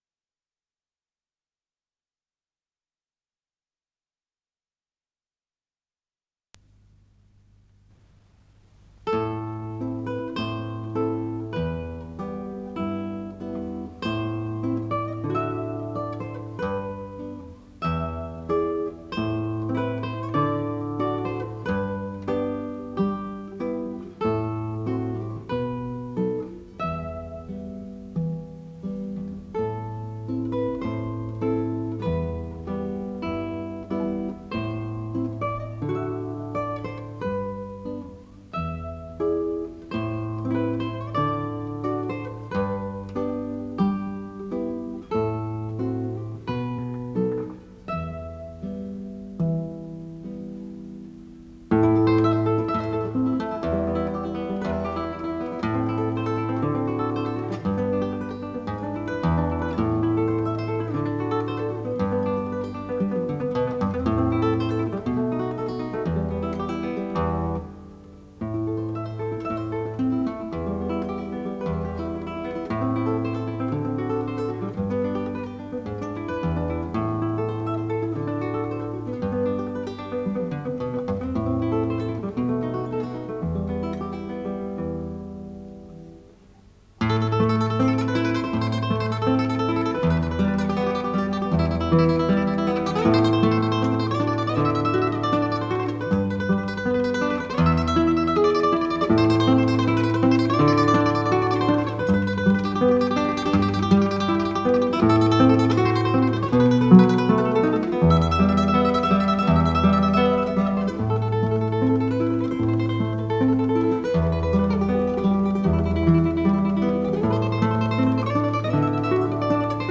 с профессиональной магнитной ленты
гитара